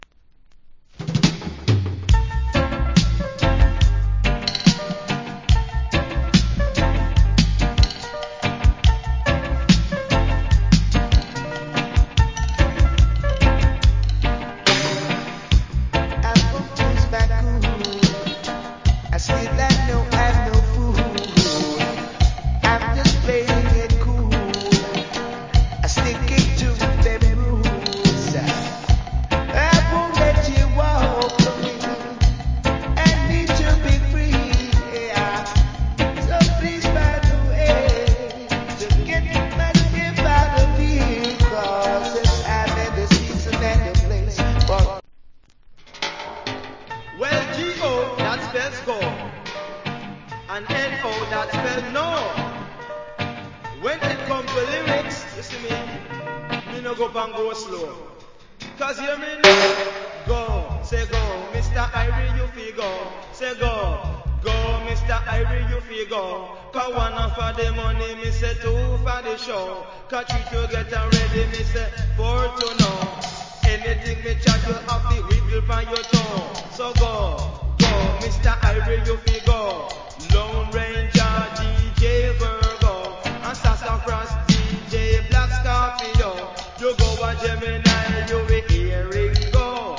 Early 80's Cool Roots Rock Vocal.